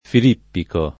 Filippico [ fil & ppiko ]